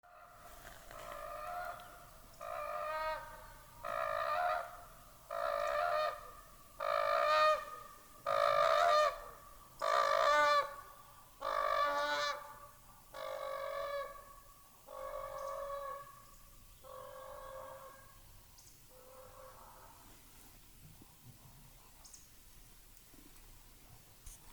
Birds -> Cranes ->
Common Crane, Grus grus
StatusSpecies observed in breeding season in possible nesting habitat